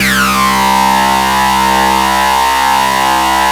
SUPER FUZZ-R.wav